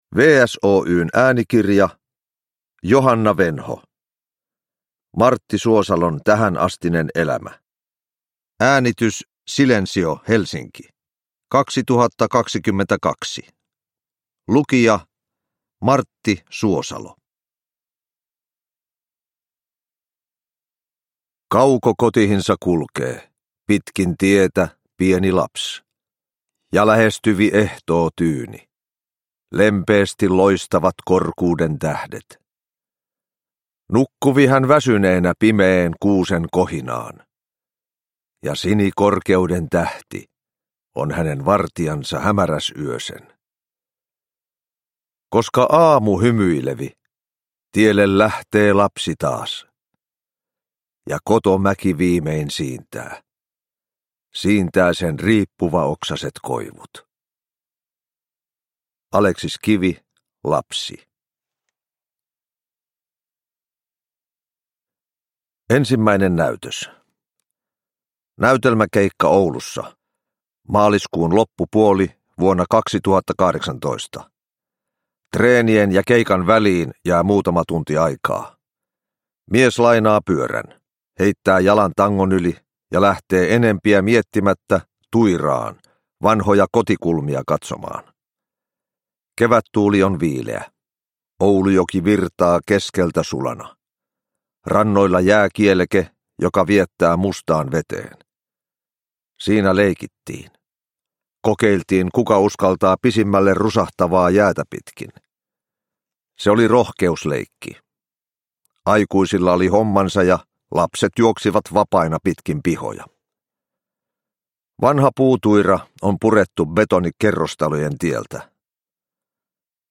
Martti Suosalon tähänastinen elämä – Ljudbok – Laddas ner